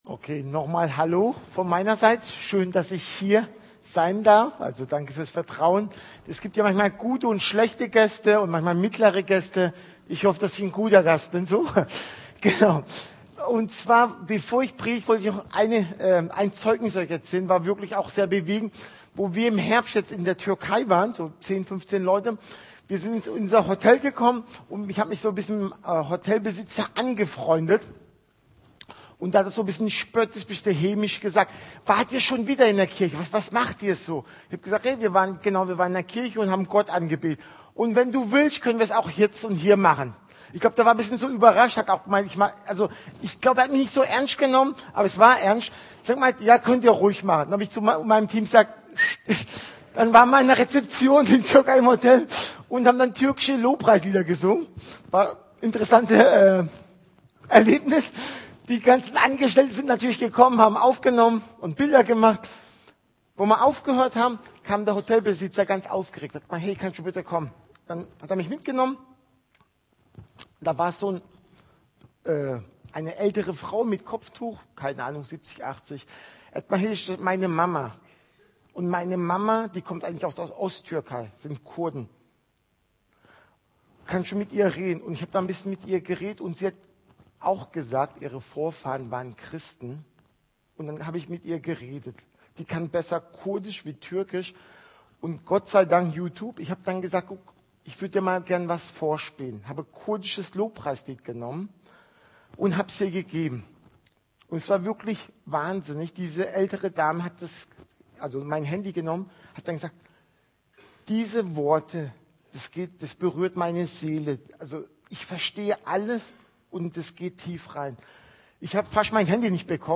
März 2025 Predigt Apostelgeschichte , Neues Testament Mit dem Laden des Videos akzeptieren Sie die Datenschutzerklärung von YouTube.
Missionsgottesdienst